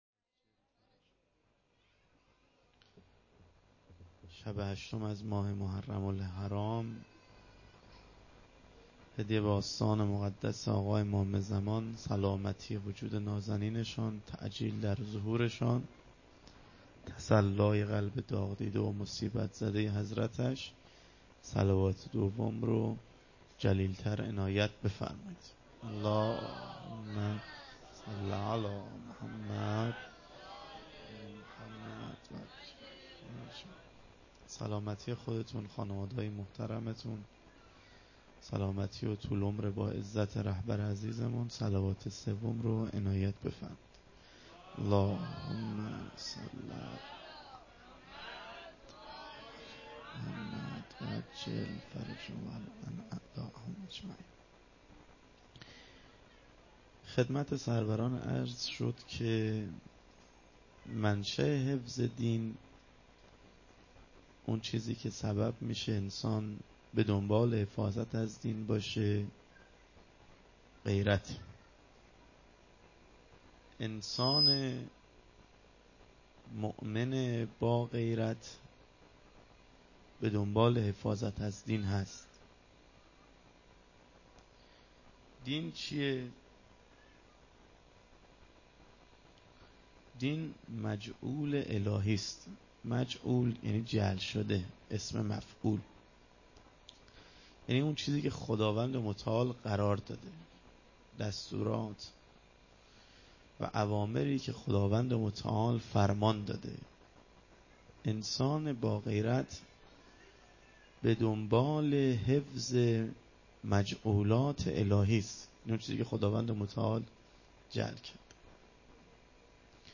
سخنرانی شب هشتم محرم 1398
هیات انصارالمهدی (عج)بندرامام خمینی ره